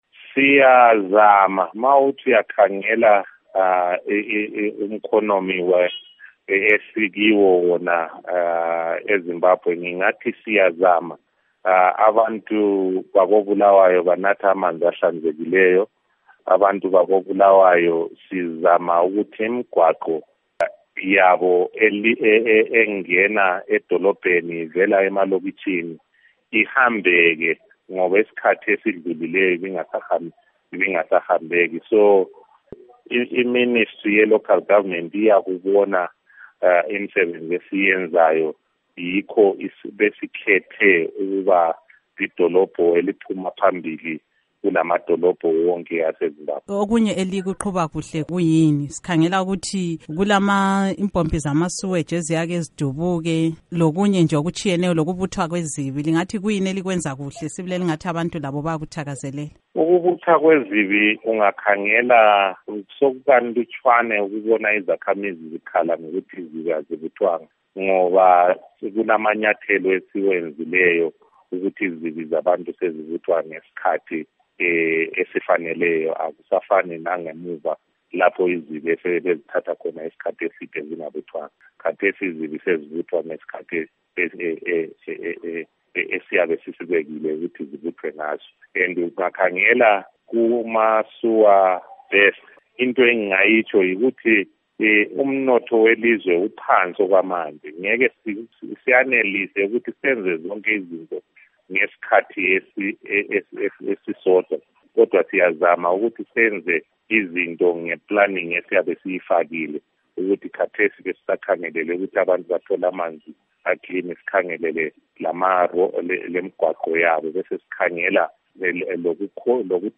Ingxoxo LoKhansila Gift Banda